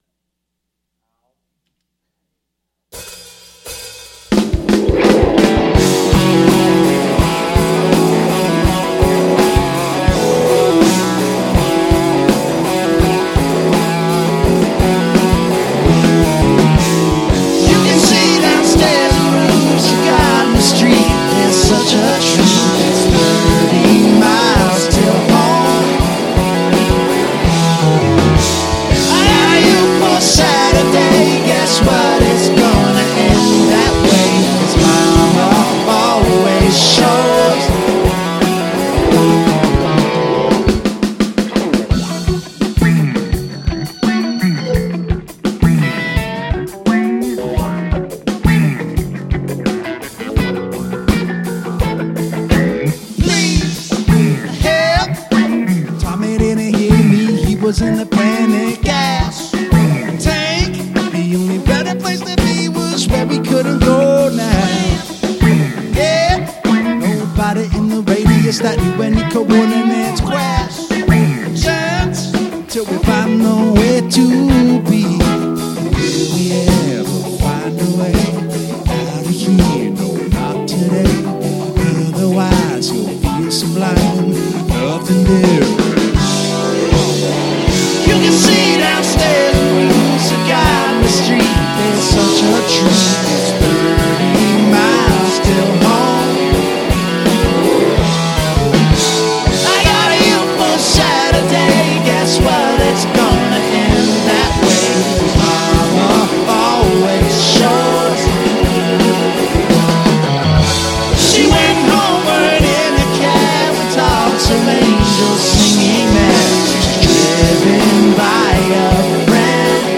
Jam Rock